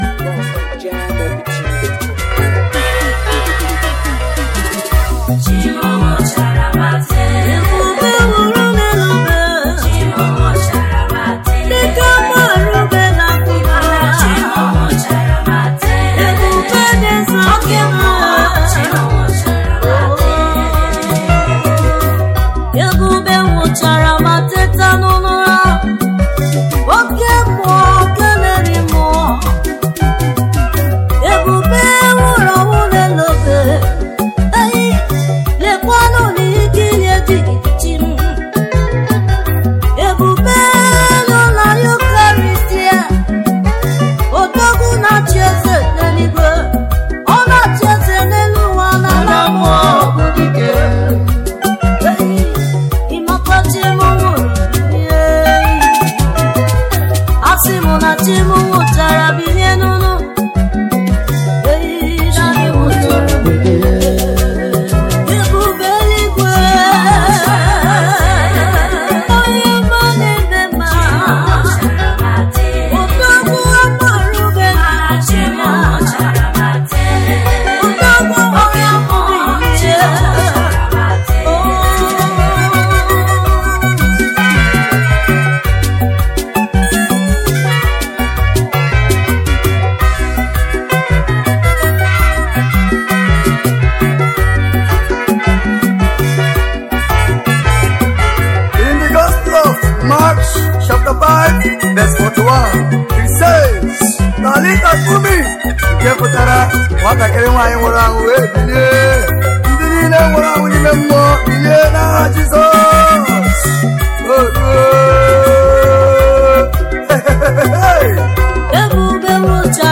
praise themed